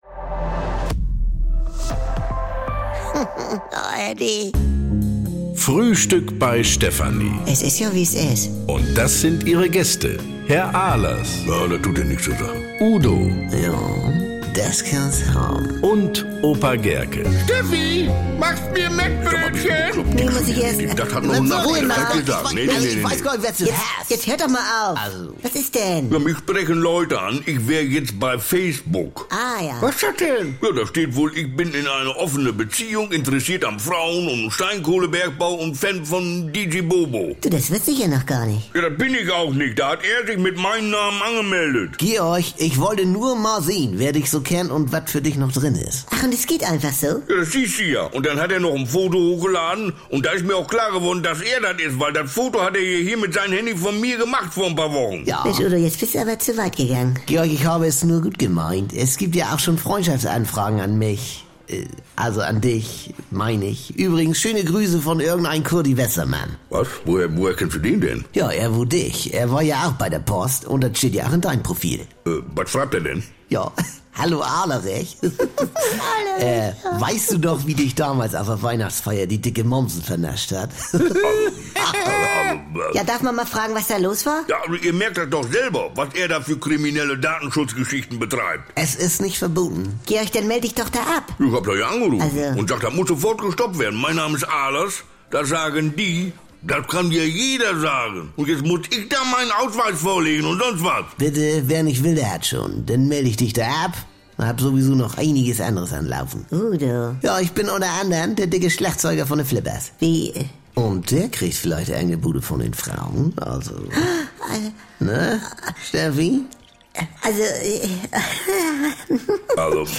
Endlich wieder Mettbrötchen, Schnorrer-Tipps, Pyro-Fantasien und brummeliges Gemecker: "Frühstück bei Stefanie" ist wieder da. Die besten Folgen der Kult-Comedy gibt es im Radio bei den NDR Landesprogrammen in Niedersachsen, Mecklenburg-Vorpommern, Schleswig-Holstein und Hamburg.